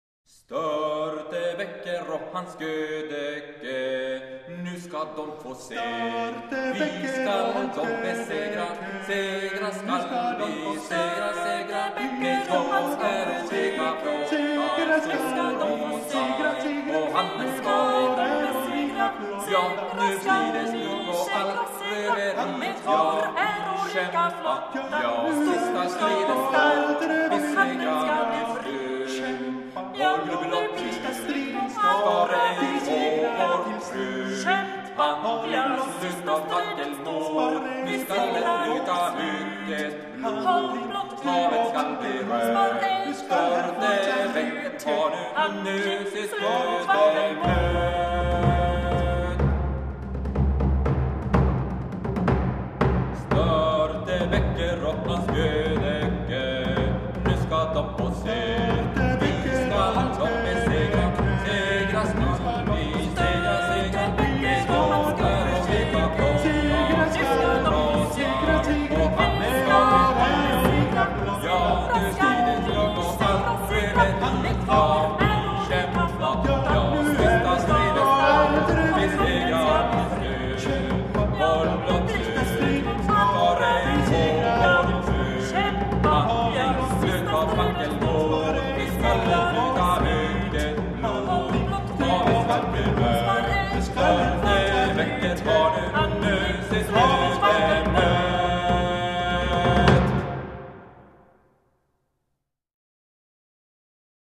Canon